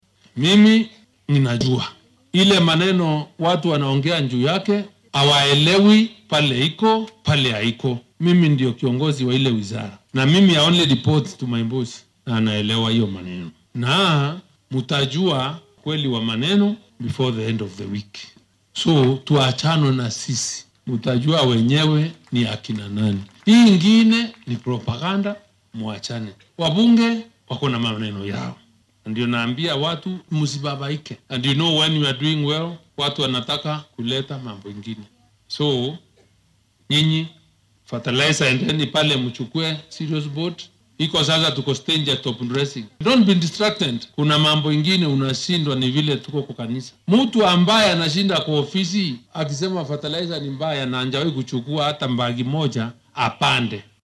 Xilli uu ka hadlayay munaasabad lacag lagu uruurinayay oo ka dhacday deegaanka Bartamaha Imenti ee ismaamulka Meru ayuu sheegay in aan xil ka xayuubin lagu cabsi gelin karin.